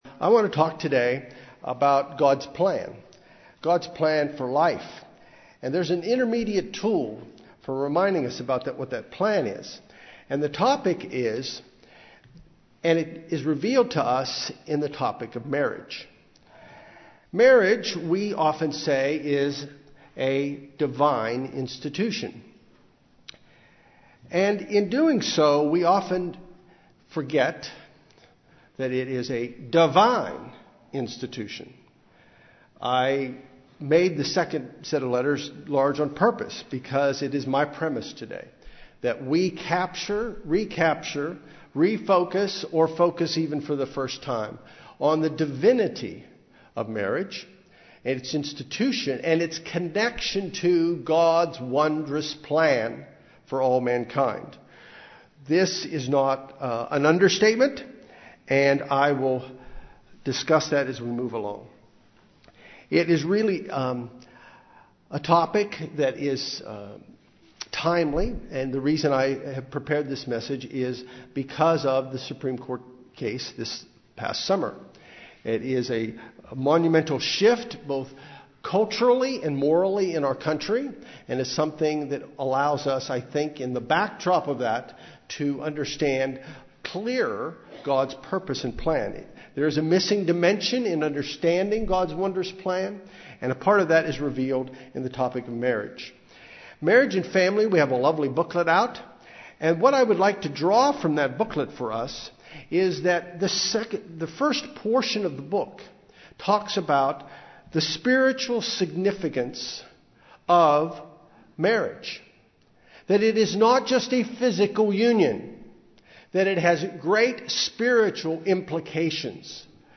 Given in Los Angeles, CA
UCG Sermon Studying the bible?